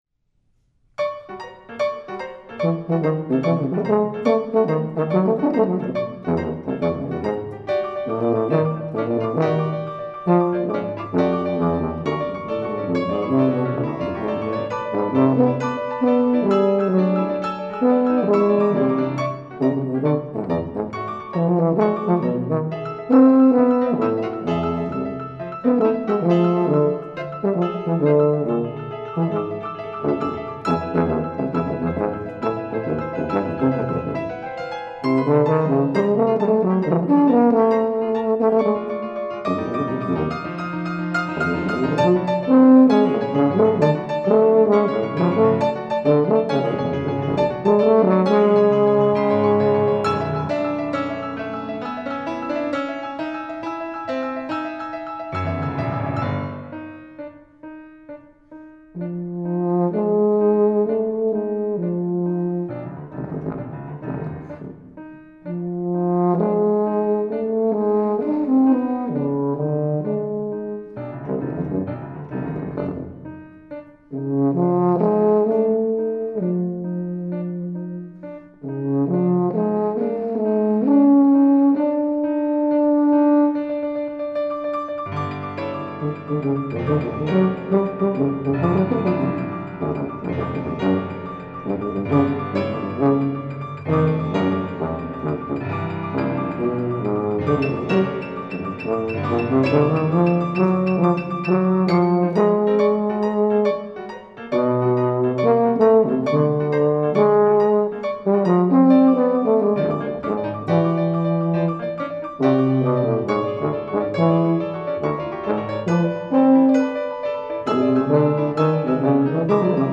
Faculty Recital 4-5-2012
Sonata for Tuba and Piano, Opus 704 by Carson Cooman